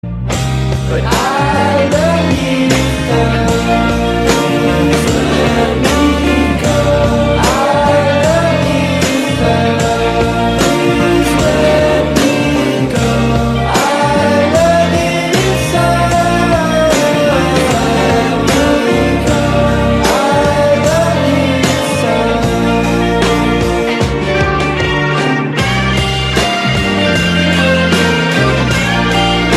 This is a Trending Emotional Song Ringtone in High Quality.